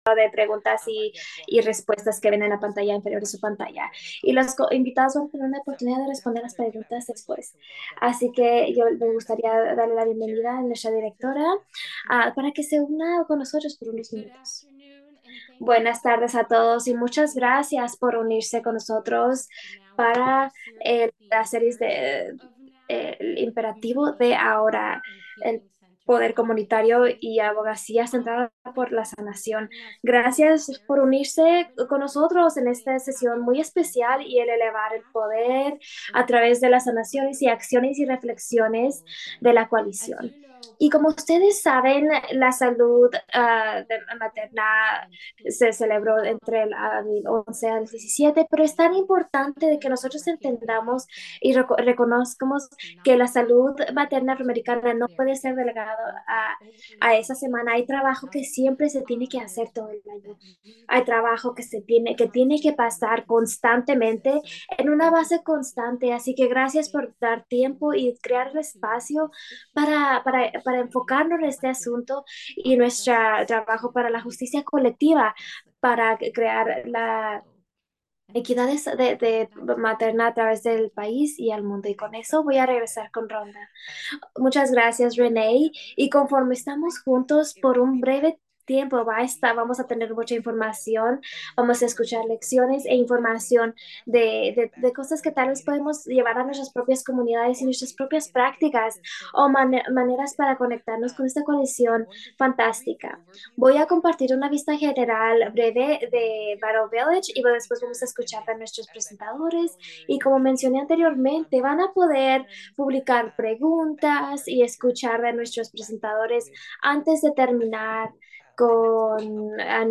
How can we build community power to achieve equity and justice for birthing families? Join us for the second installment of the 2024 NOW webinar series with a dynamic conversation with members of the Greater Boston Birth Equity Coalition (GBBEC).